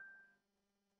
Button Click
A clean, satisfying button click with subtle tactile feedback and brief resonance
button-click.mp3